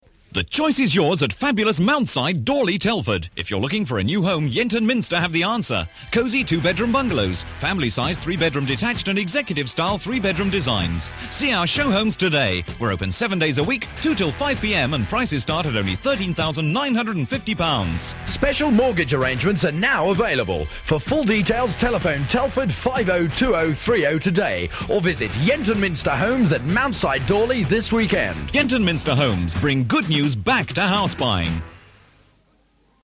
Beacon Radio advert for new houses in Telford